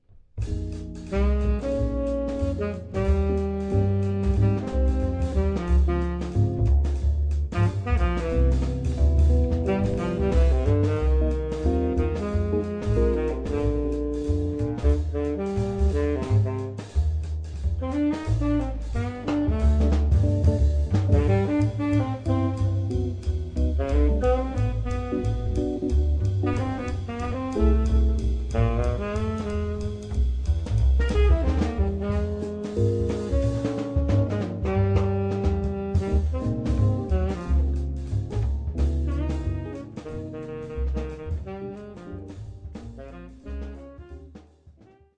Saxophone, Guitar,Bass and Drums Quartet